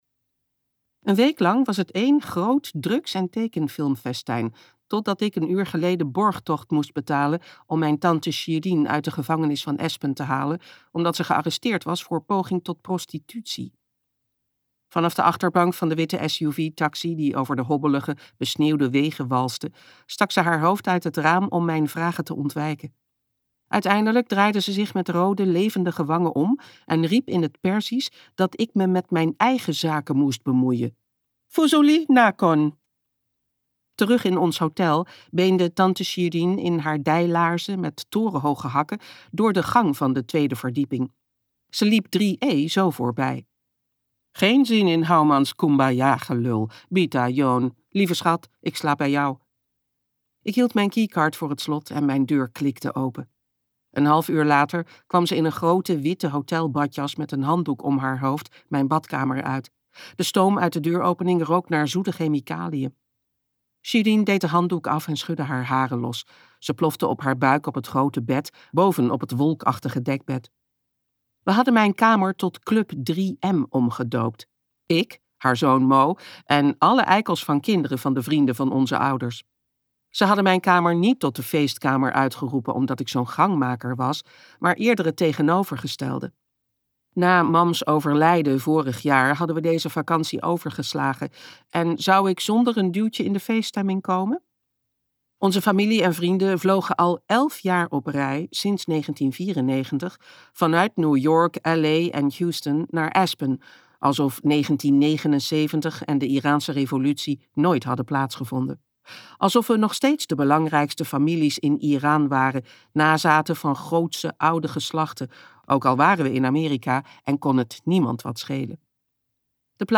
Ambo|Anthos uitgevers - De perzen luisterboek